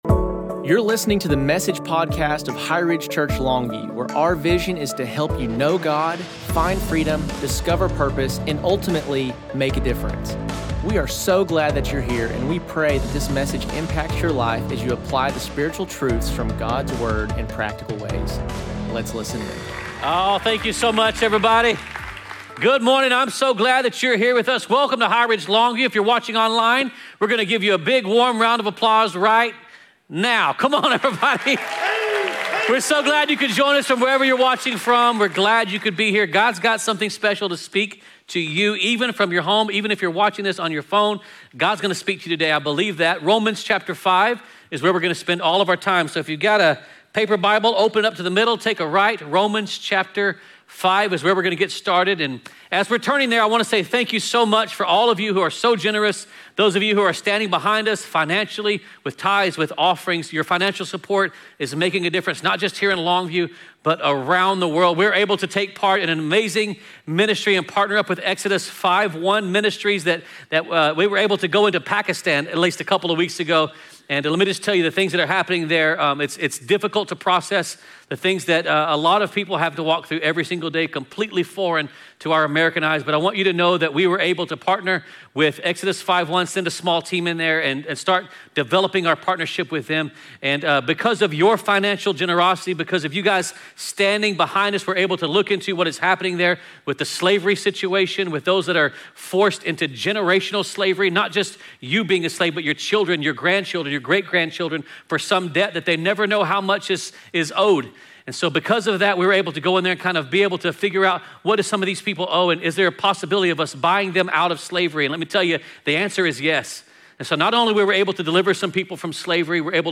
Sunday, July 27, 2025 Message: Grace.